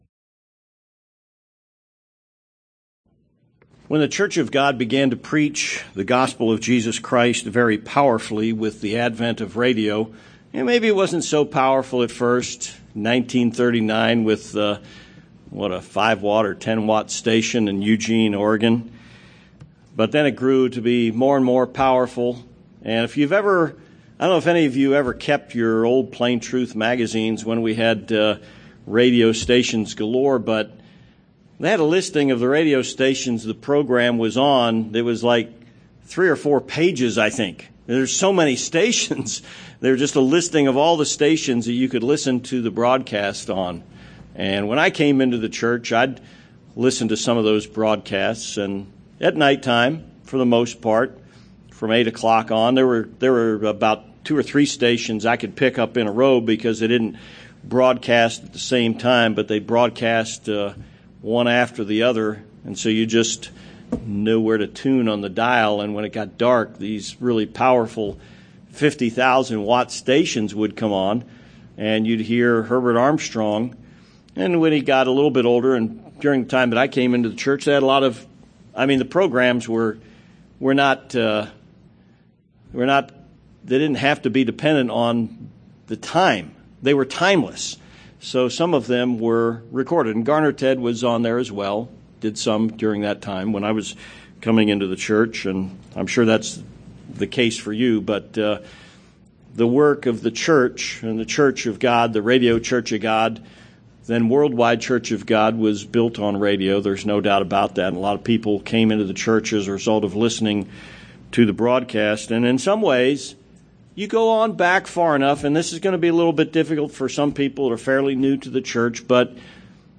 Sermons
Given in El Paso, TX Tucson, AZ